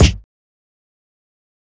SouthSide Kick (12).wav